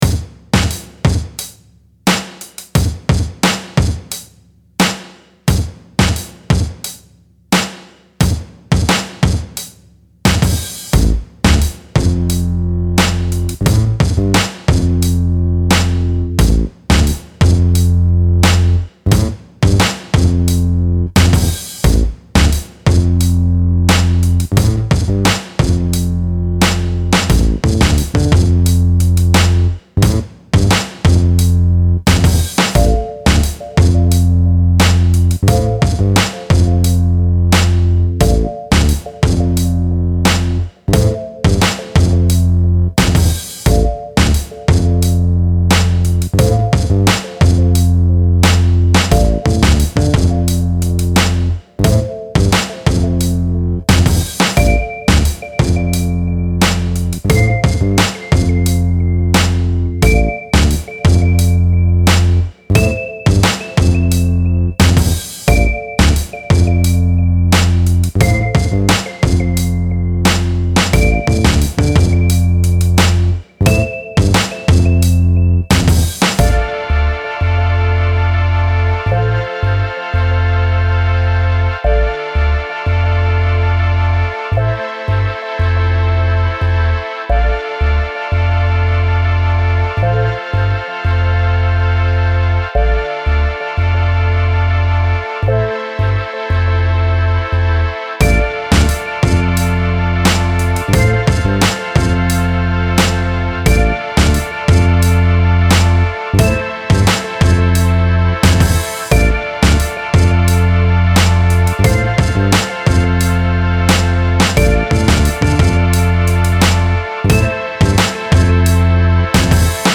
Style Style Hip-Hop
Mood Mood Cool, Relaxed
Featured Featured Bass, Drums, Mellotron +1 more
BPM BPM 88